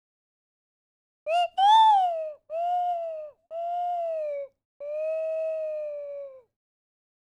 calming1.wav